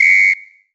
LWHISTLE 2.wav